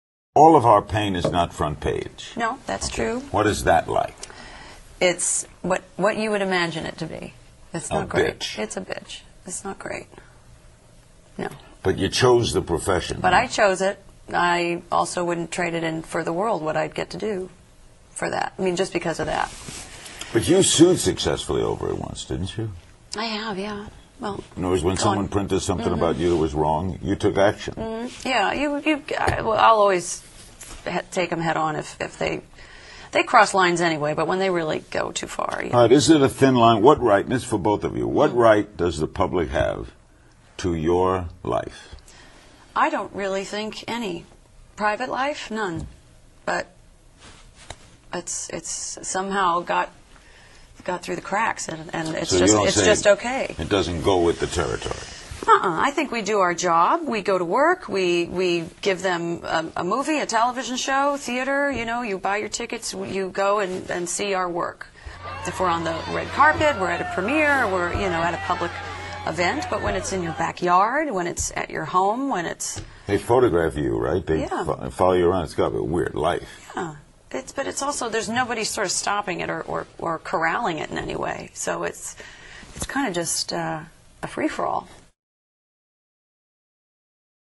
CNN 采访Jennifer Aniston 听力文件下载—在线英语听力室